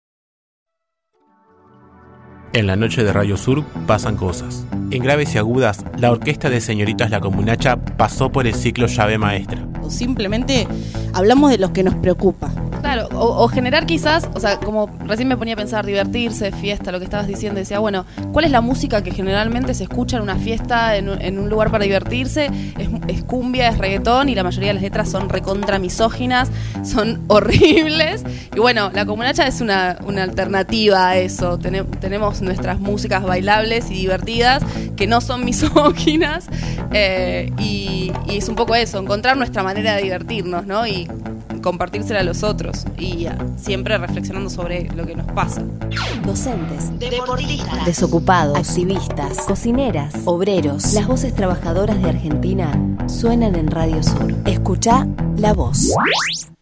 En una versión acústica, la banda tocó en vivo